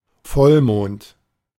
English: Pronunciation recording of German term " Vollmond ". Male voice, recorded by native German speaker from Berlin, Germany.
Männliche Stimme, aufgenommen von deutschem Muttersprachler aus Berlin, Deutschland.
De-Vollmond.ogg.mp3